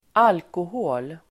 Uttal: [²'al:kohå:l]